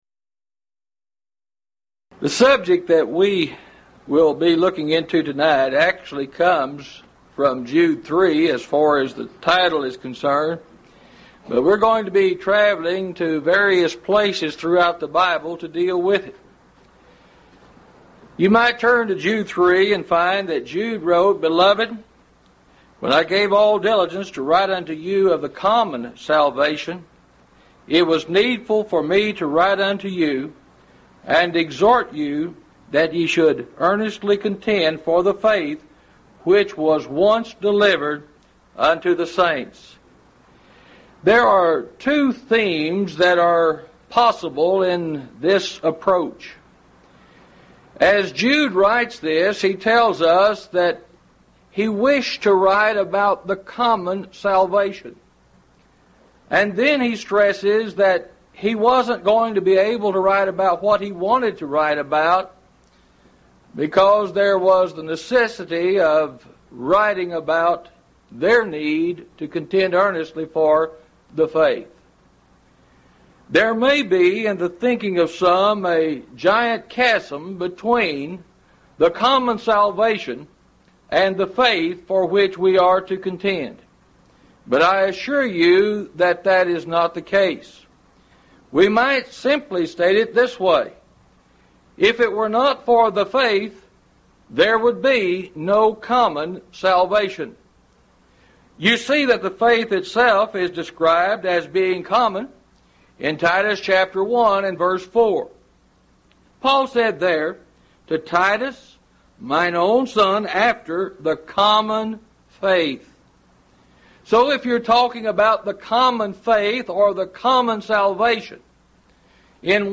Event: 1st Annual Lubbock Lectures Theme/Title: The Faith Once For All Delivered
lecture